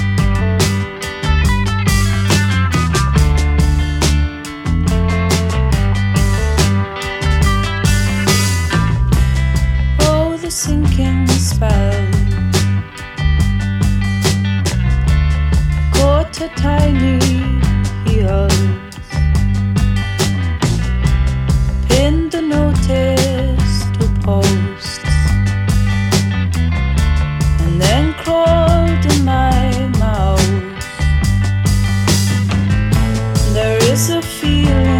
Alternative Pop Pop Rock Rock Psychedelic
Жанр: Поп музыка / Рок / Альтернатива